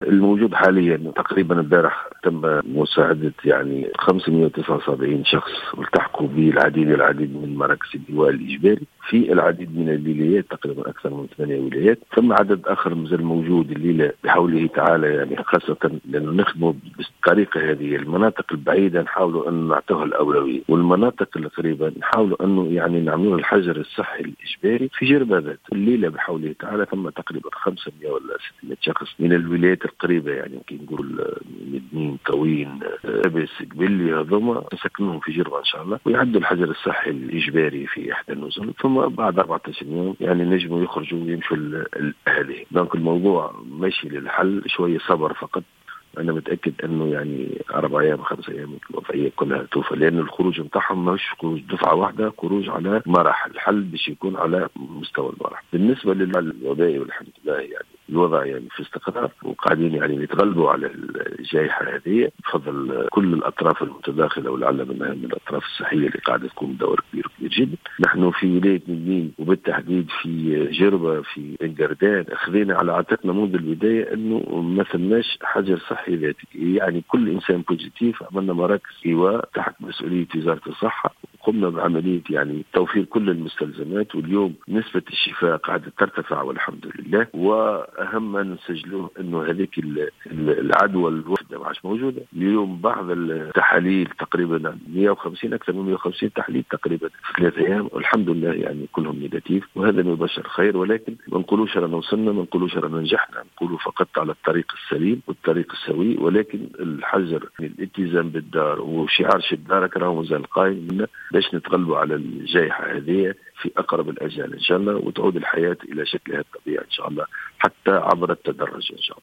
قال والي مدنين، الحبيب شواط في تصريح اليوم لـ"الجوهرة أف أم" إن خروج العالقين في جزيرة جربة سيتم على مراحل.